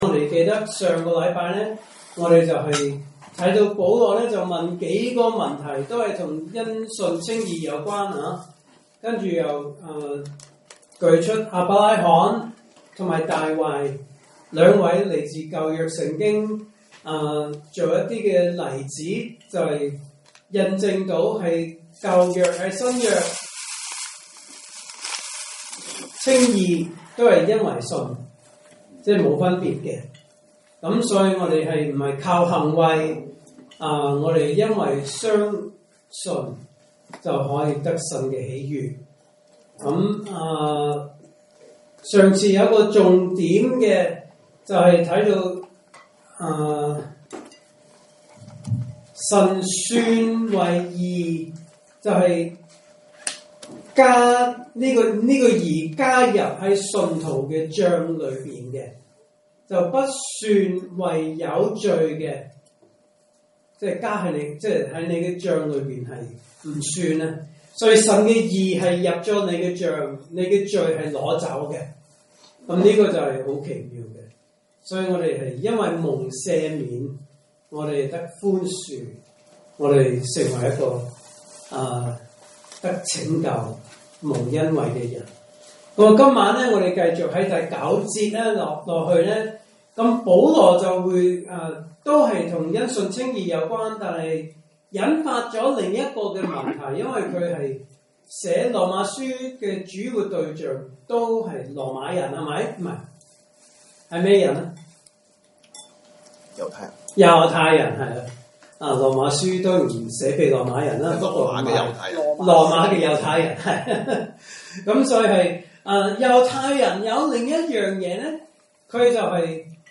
證道信息